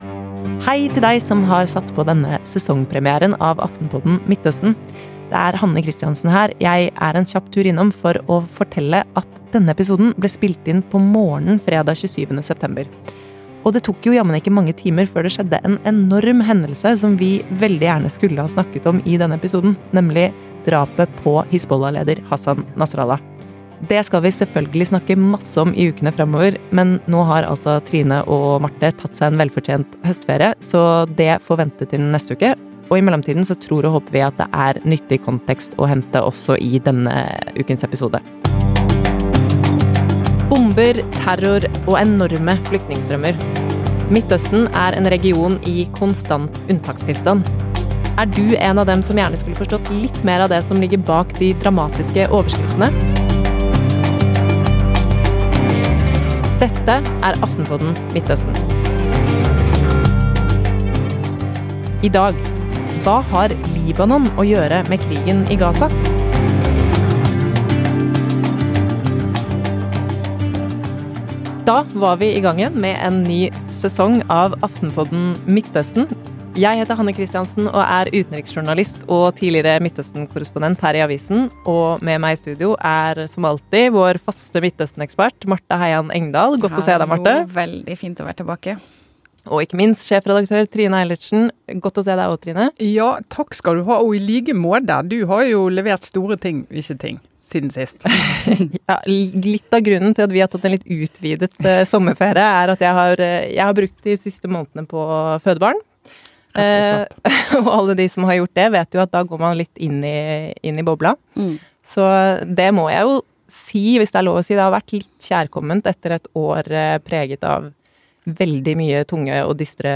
Hva har egentlig dette vakre, men skakkjørte landet med krigen i Gaza å gjøre? I studio